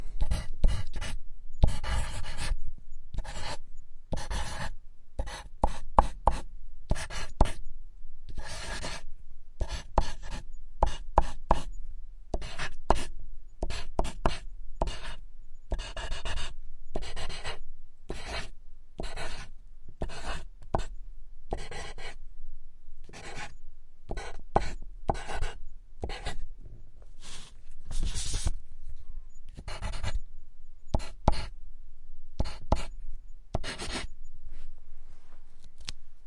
写作 " 在玻璃上用铅笔在纸上快速书写 2
描述：记录在带有SM81和便宜的akg SDC的SD 702上，不记得哪一个只是想要变化。不打算作为立体声录音只有2个麦克风选项。没有EQ不低端滚动所以它有一个丰富的低端，你可以驯服品尝。
标签： 涂鸦 绘图 玻璃 写入
声道立体声